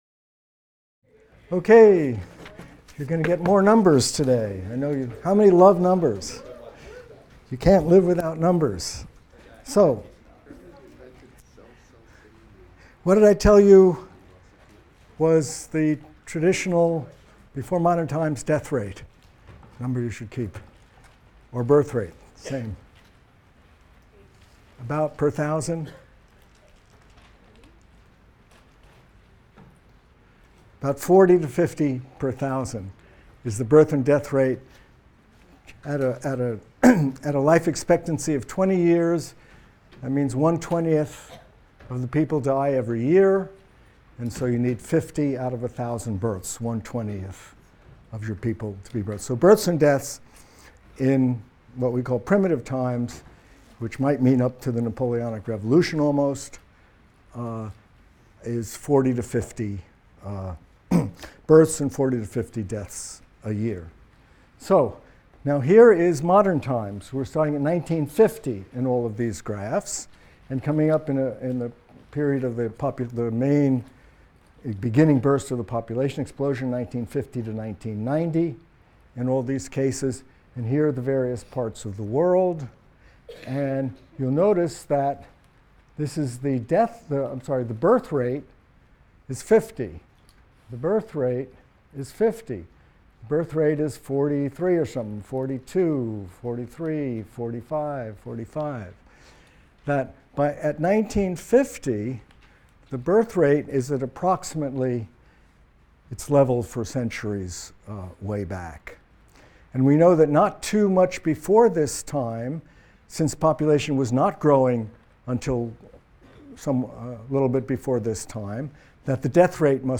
MCDB 150 - Lecture 14 - Demographic Transition in Developing Countries | Open Yale Courses